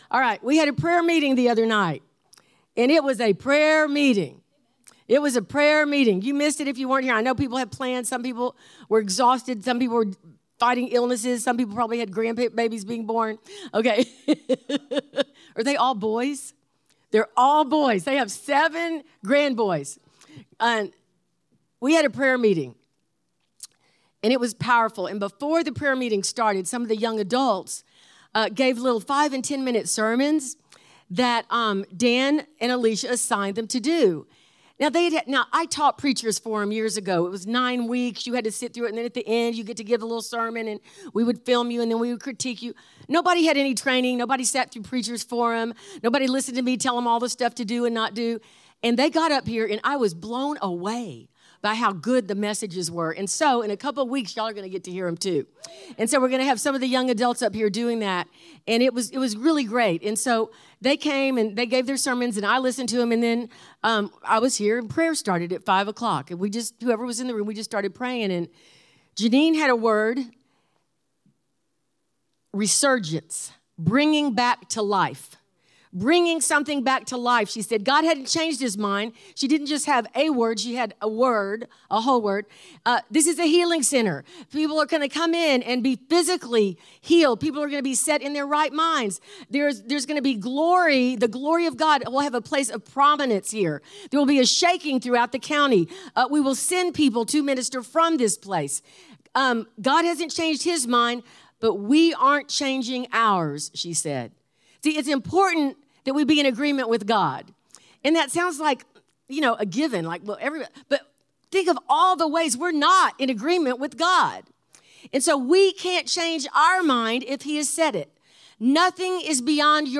SERMONS
Epicenter Church